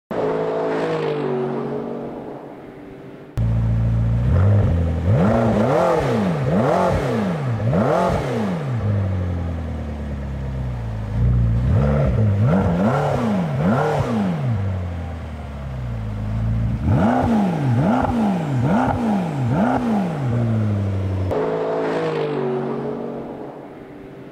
ENGINE SIZE 3.8 L V8 Twin Turbo